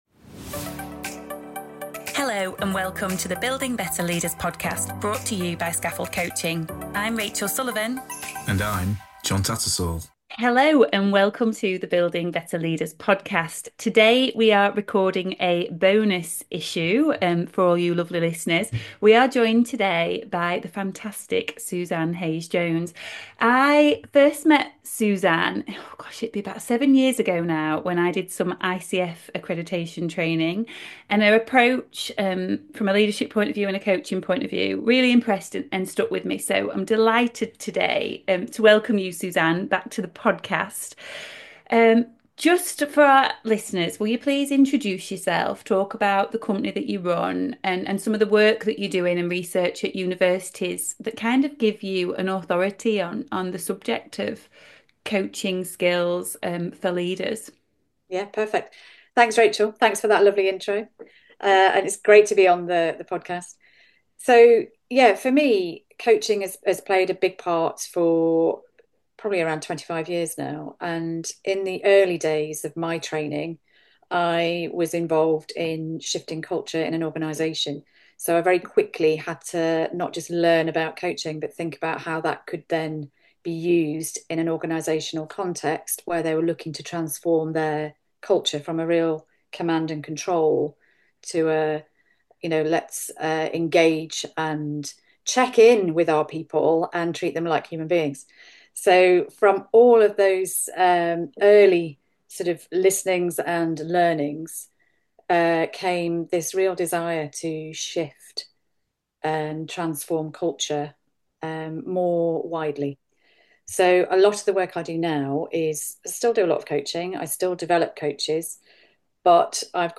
In this podcast conversation